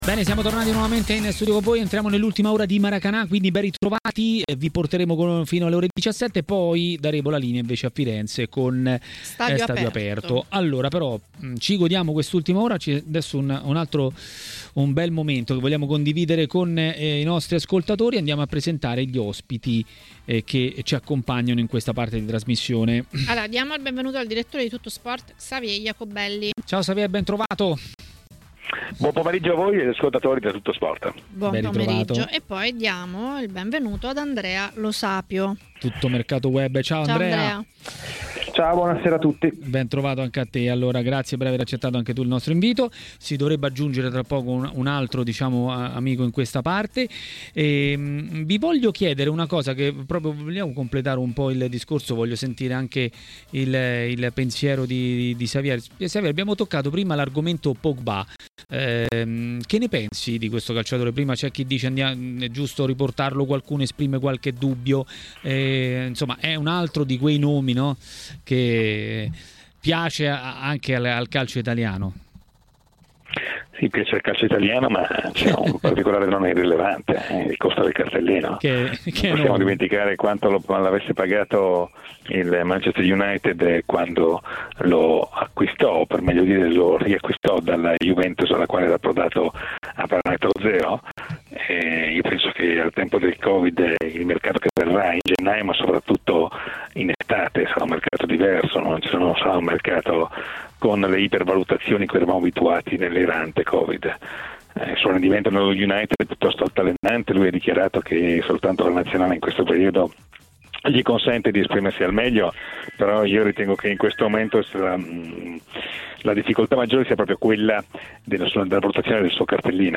L'ex calciatore dell'Atalanta Damiano Zenoni a TMW Radio, durante Maracanà, ha parlato del momento dei bergamaschi.